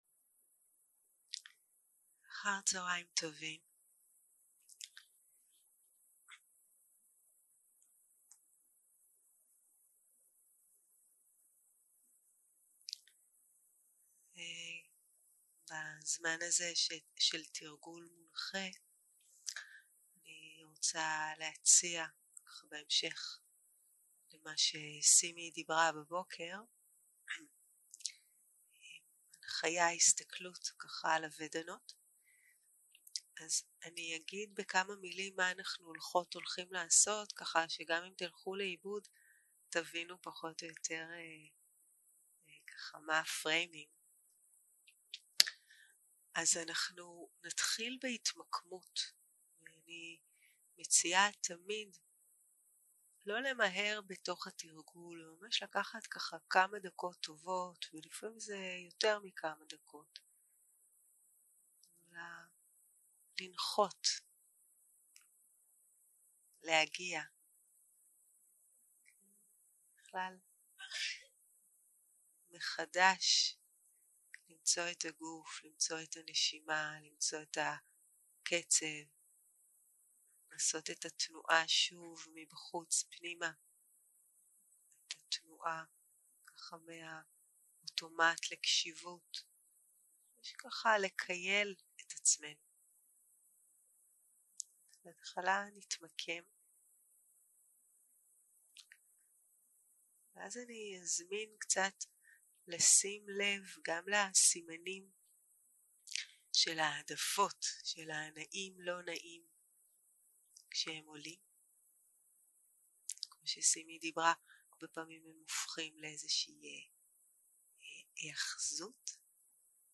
יום 3 - הקלטה 6 - צהרים - מדיטציה מונחית
Dharma type: Guided meditation